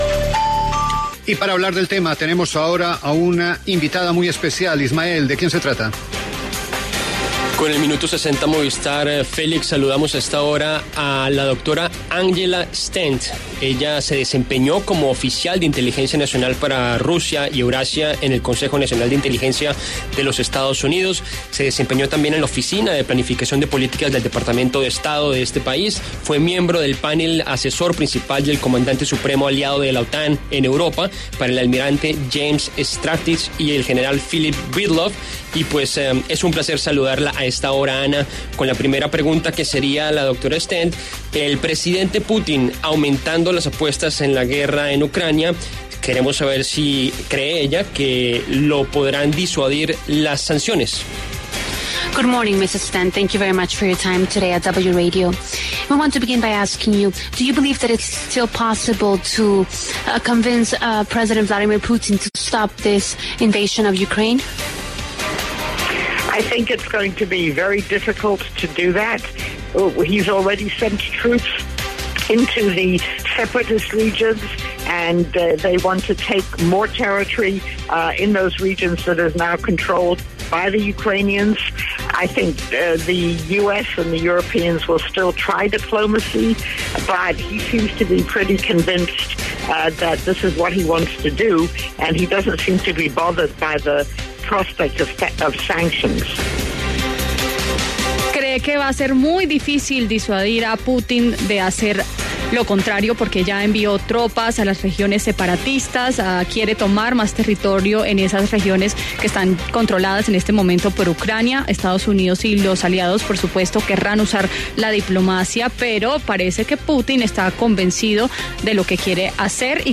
Angela Stent, quien se desempeñó como oficial de inteligencia nacional para Rusia y Eurasia en el Consejo Nacional de Inteligencia de EE.UU., habló en La W a propósito de la tensión entre Rusia y Ucrania.
En el encabezado escuche la entrevista completa con Angela Stent, quien se desempeñó como oficial de inteligencia nacional para Rusia y Eurasia en el Consejo Nacional de Inteligencia de Estados Unidos.